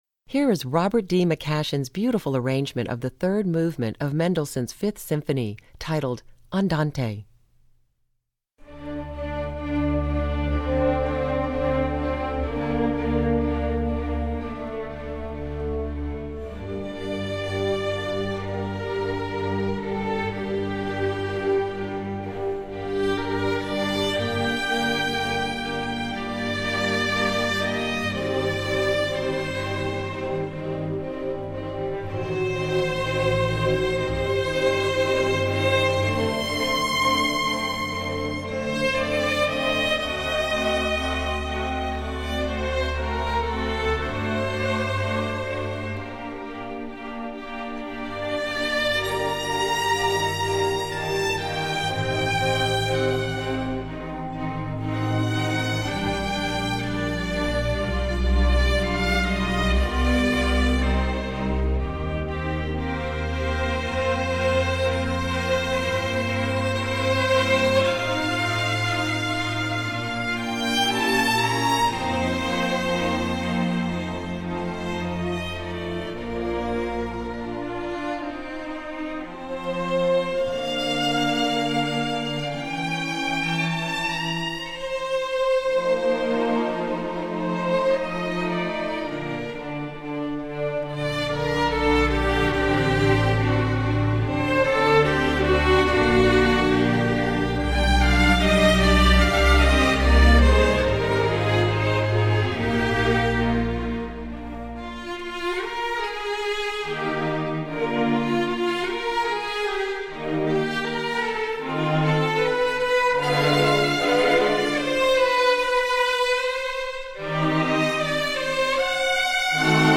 Composer: Scottish Traditional
Voicing: String Orchestra